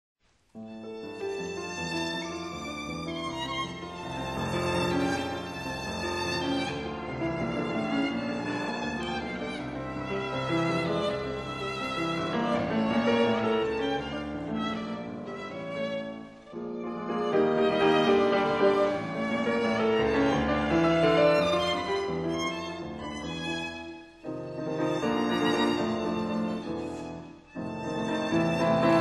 第1楽章　アレグロ　ニ短調
ドイツの民謡や、ハンガリーのジプシー音楽の語法を研究し、ロマン主義文学の影響や生来の詩情とによる独特の重厚な作風が特徴としてあげられる。
Piano